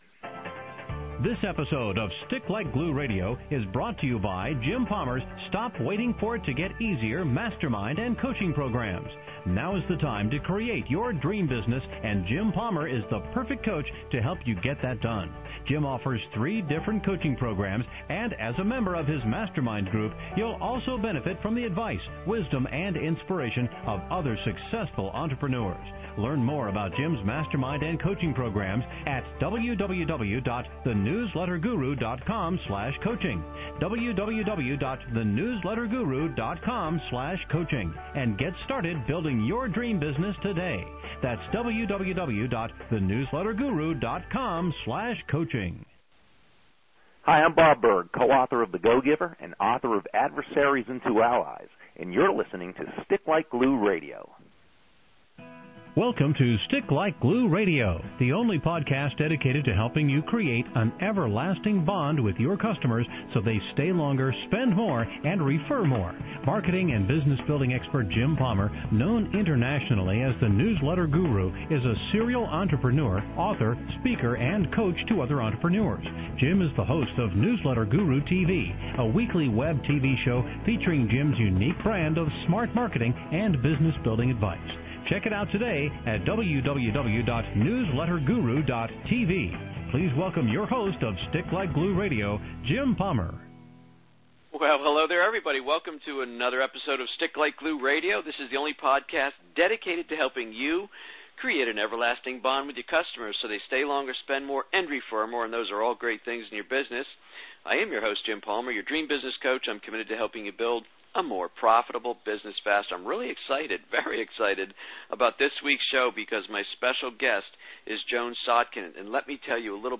Main Questions Asked Can you share your backstory with us? Why do some people make a lot of money and others remain in the same position struggling?